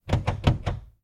Звук нажатия педали газа машины